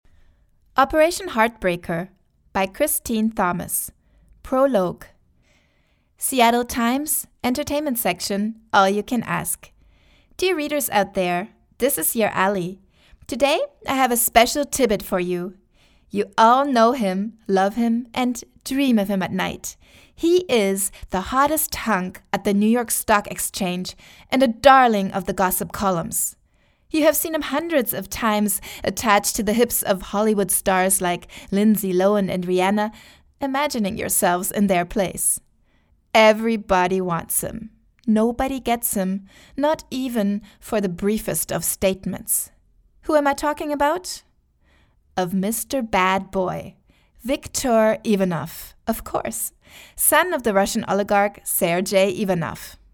Sprechprobe: Sonstiges (Muttersprache):
german female voice over artist, actor